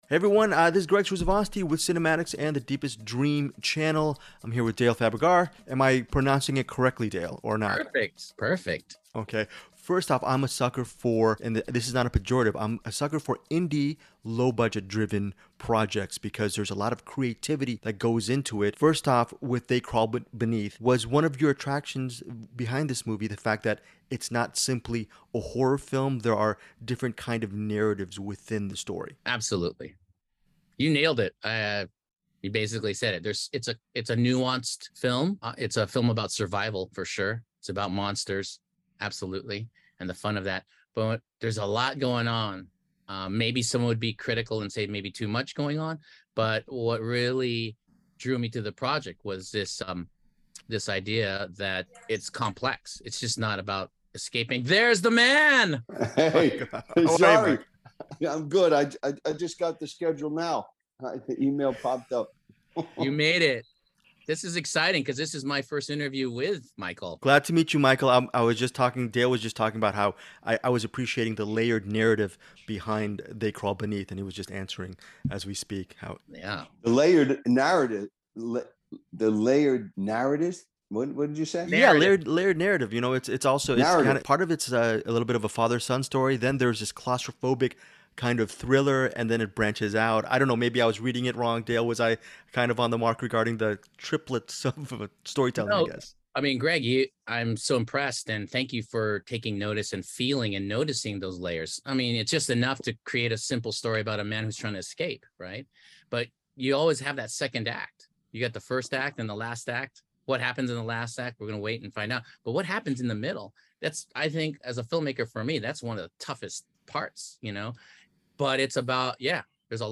'They Crawl Beneath' Interview